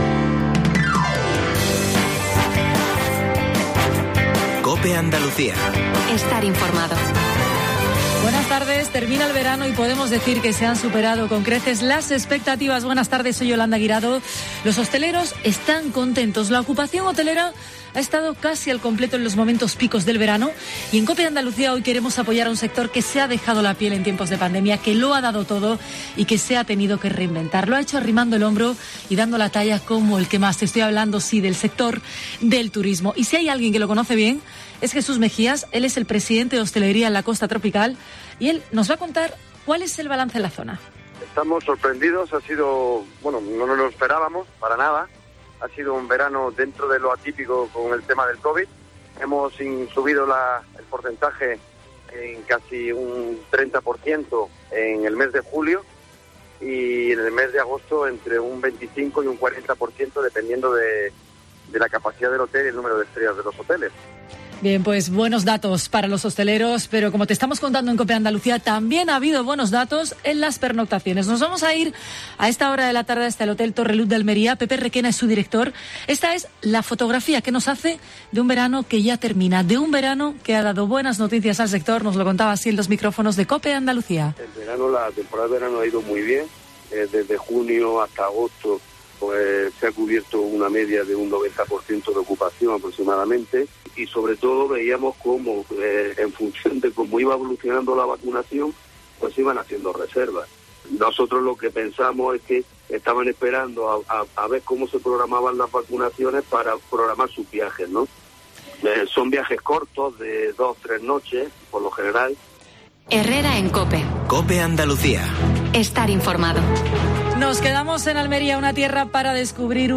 AUDIO: Entrevista a Vicente García Egea (delegado Provincial de Turismo y coordinación de Vicepresidencia de la Junta de Andalucía en Almería).